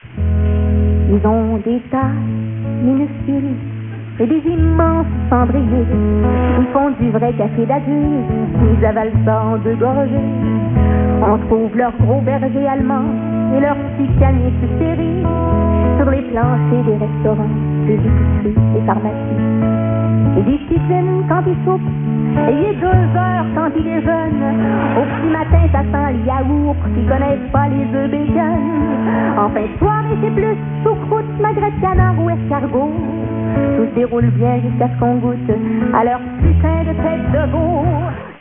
Nous vous proposons un extrait de son spectacle.